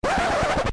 Cue Scratch
cue error fail mistake oops record request scratch sound effect free sound royalty free Memes